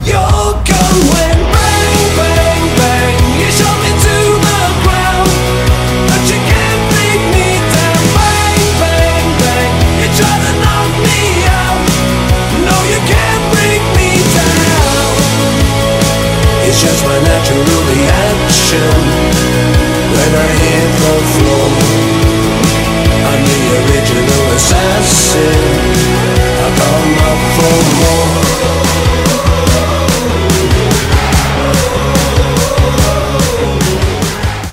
• Качество: 192, Stereo
позитивные
мужской вокал
Alternative Rock
бодрые
post-punk